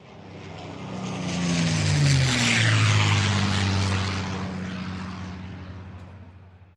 Airplane, WW2 Grumman Tmb-3e Avenger, Pass Overhead